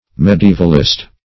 medievalist - definition of medievalist - synonyms, pronunciation, spelling from Free Dictionary
Medievalist \Me`di*e"val*ist\ .